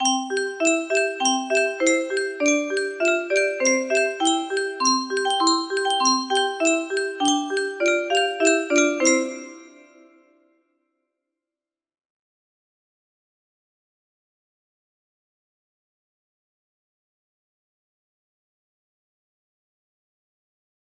Wow! It seems like this melody can be played offline on a 15 note paper strip music box!